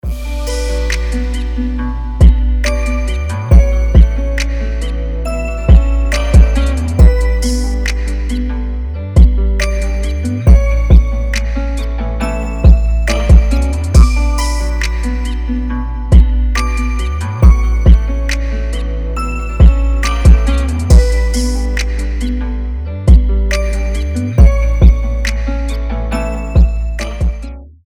• Качество: 320, Stereo
без слов
красивая мелодия
инструментальные
Chill Trap
beats
Красивая, спокойная музыка